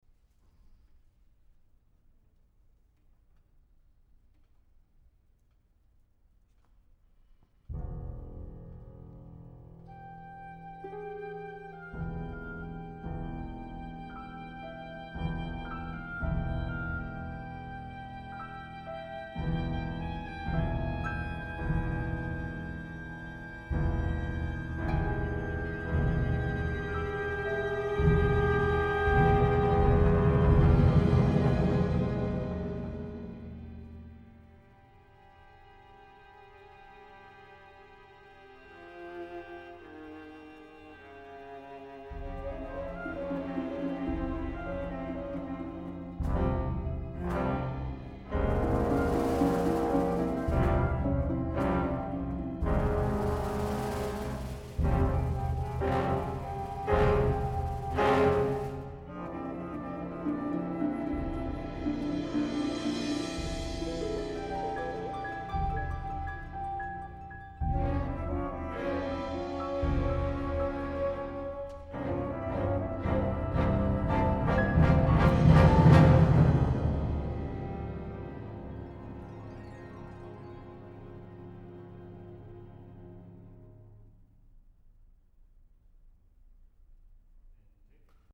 Music Only!